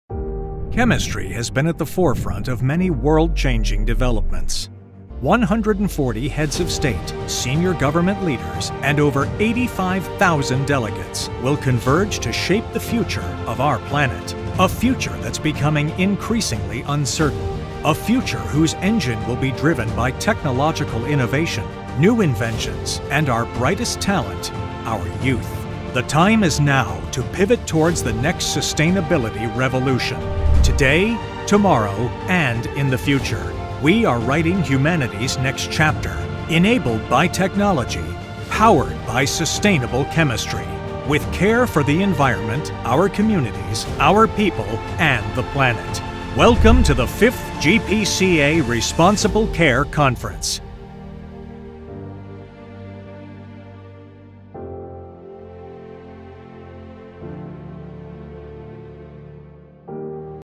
Male
English (American)
Corporate Videos